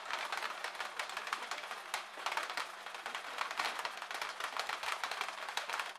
Rain on Window
Steady rain falling on a glass window with individual droplet impacts and running rivulets
rain-on-window.mp3